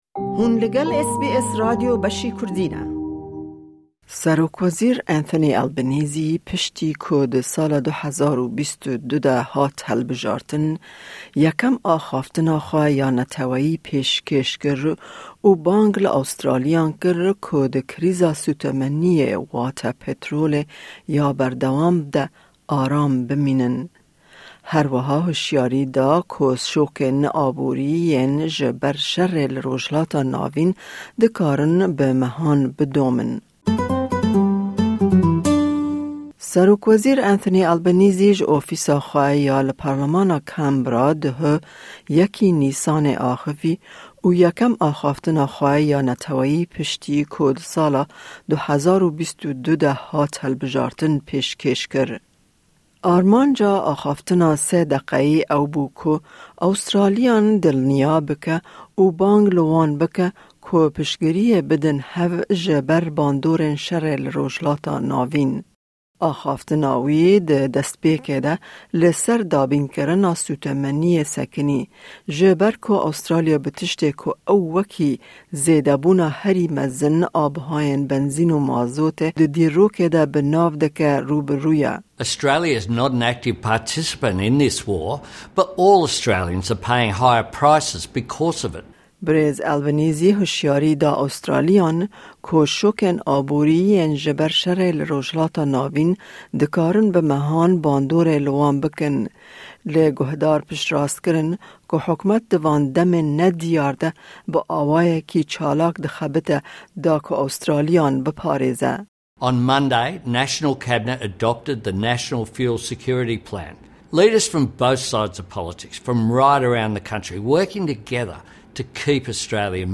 Keep calm and carry on: PM delivers address to the nation
Prime Minister Anthony Albanese has delivered his first National Address since being elected in 2022, calling for Australians to remain calm amid the ongoing fuel crisis. He also warns that the economic shocks as a result of the war in the Middle East could last for months.